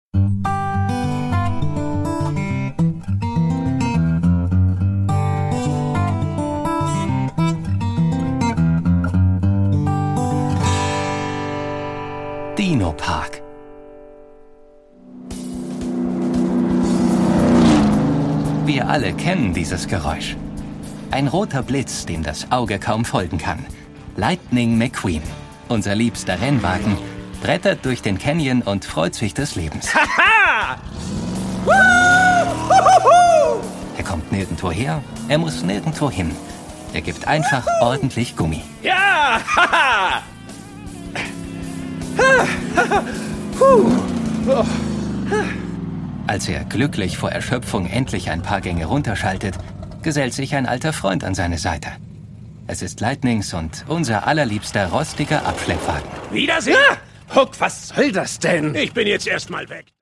Das Original-Hörspiel zur Disney/Pixar TV-Serie
Produkttyp: Hörspiel-Download